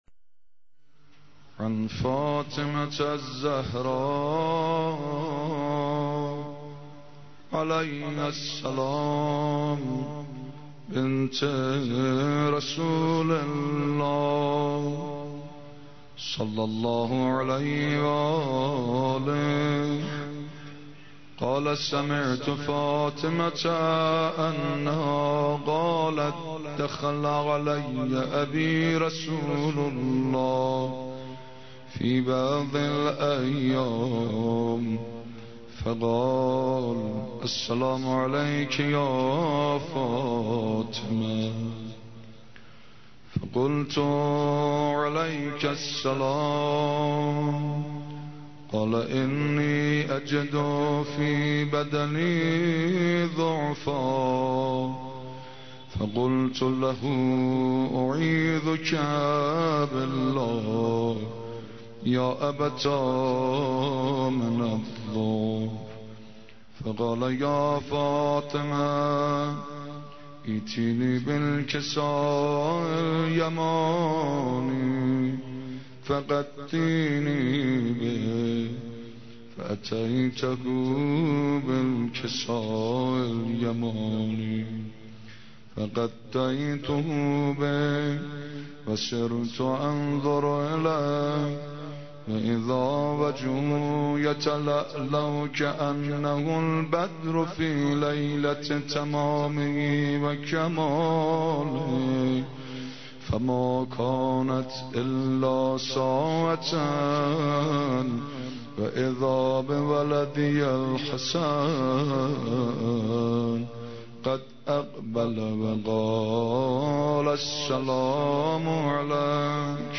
قرائت حدیث کسا